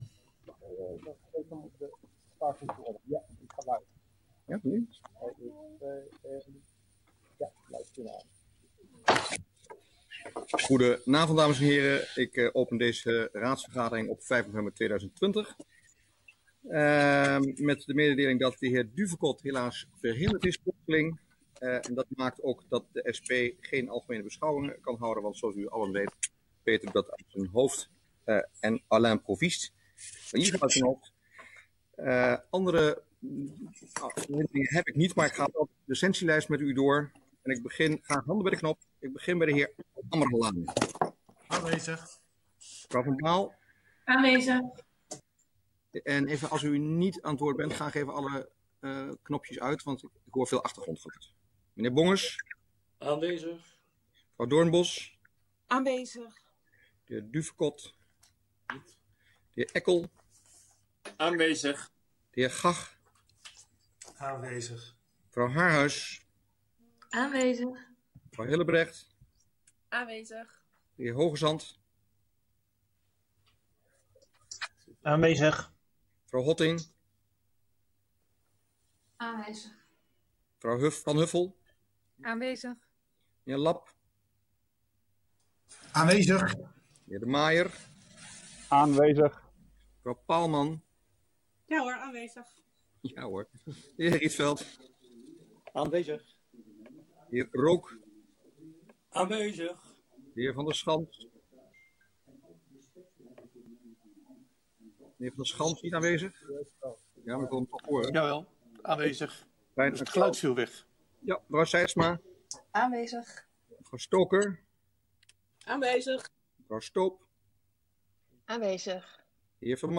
Raadsvergadering 05 november 2020 19:30:00, Gemeente Dronten
Deze vergadering wordt digitaal gehouden en is hieronder via de live stream te volgen.